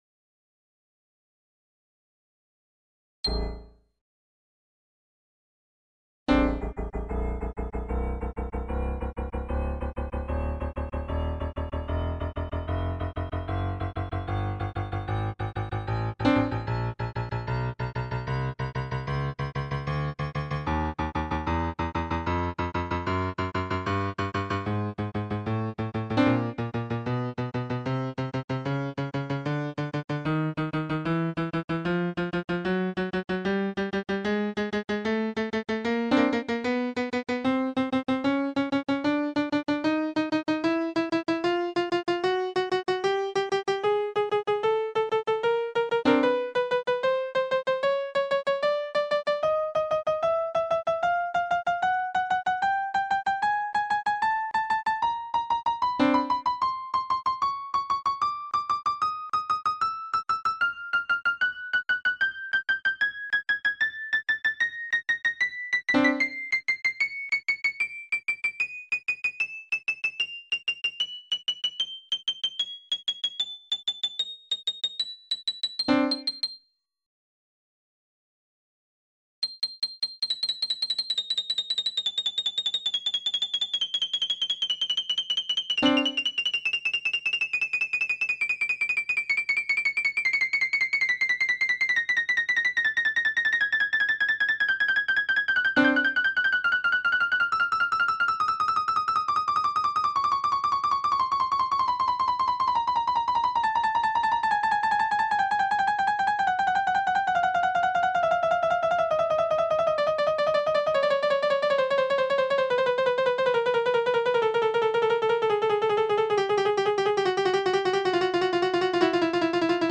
The Player-Care Test Roll is designed to help the user determine Six basic operating characteristics of any regular 88-note player piano mechanism. They are: General Leakage, Tracking, Tempo Control, Scale Uniformity, Repetition, and Capacity. Our new test roll tests ALL 88 notes.
player-care_test_roll.mp3